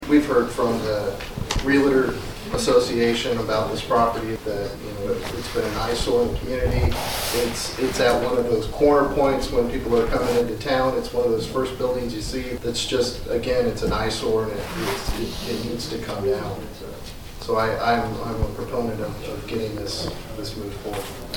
Ward 1 Councilman Leon Thompson said it’s time for the dilapidated structure to come down.